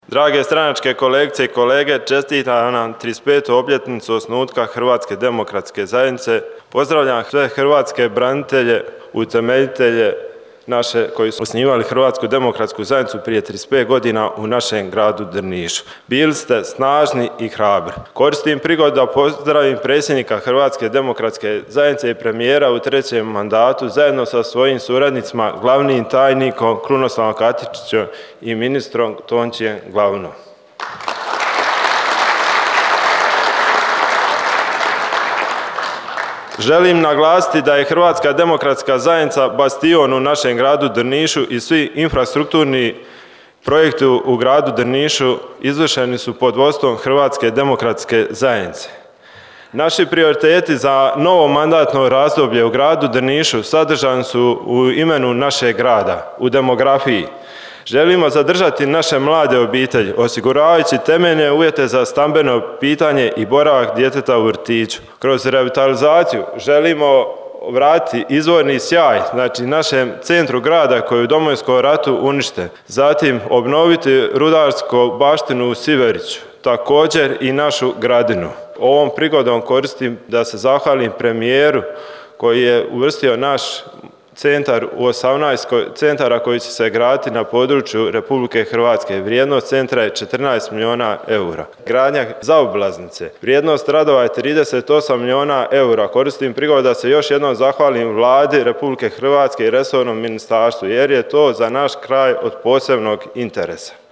Drniški HDZ proslavio 35. obljetnicu osnutka te održao predizborni skup